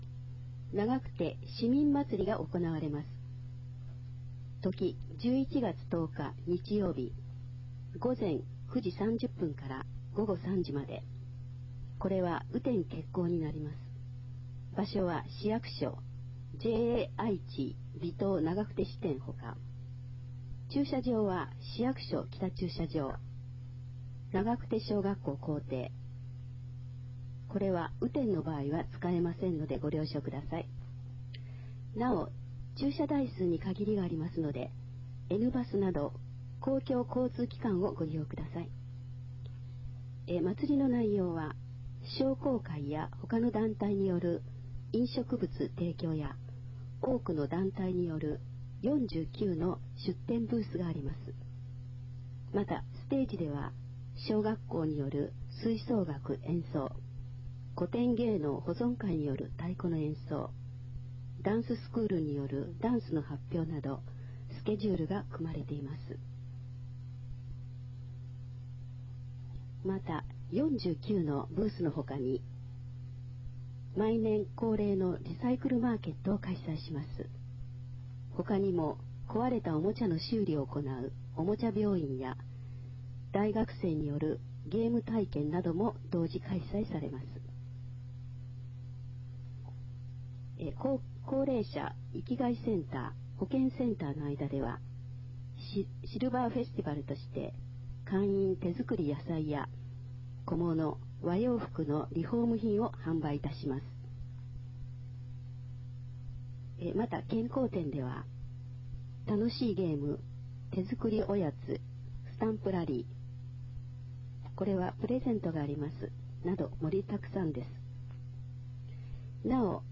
平成29年8月号から、ボランティア団体「愛eyeクラブ」の皆さんの協力により、広報ながくてを概要版として音声化して、ホームページ上で掲載しています。
音声ファイルは、カセットテープに吹き込んだものをMP3ファイルに変換したものです。そのため、多少の雑音が入っています。